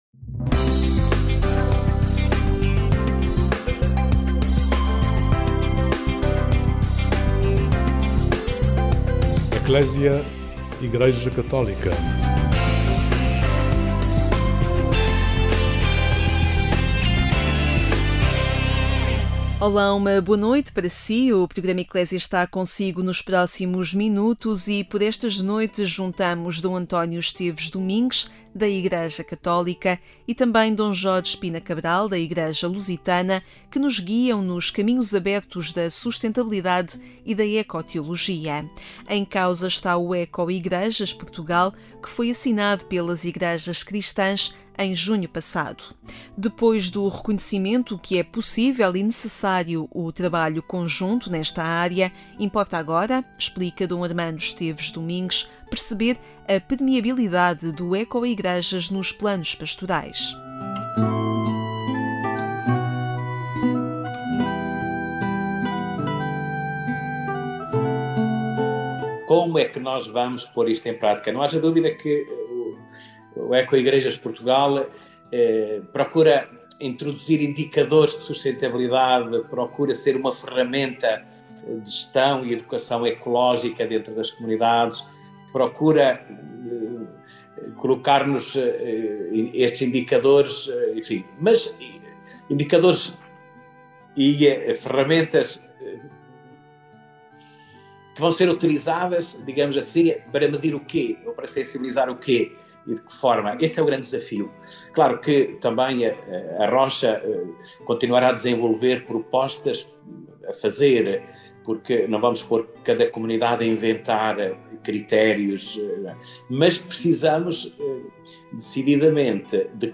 Por estes dias juntamos D. Armando Esteves Domingues, da Igreja Católica, e D. Jorge Pina Cabral, da Igreja Lusitana, que nos guiam nos caminhos abertos da sustentabilidade e da eco teologia.